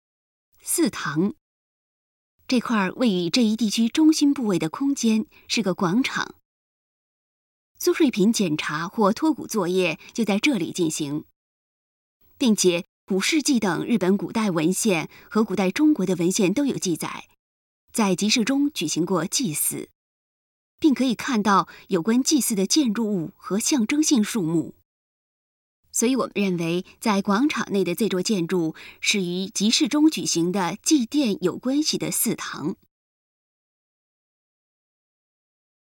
所以，我们认为，在广场内的这座建筑是集市中举行的祭奠有关系的祀堂。 语音导览 前一页 下一页 返回手机导游首页 (C)YOSHINOGARI HISTORICAL PARK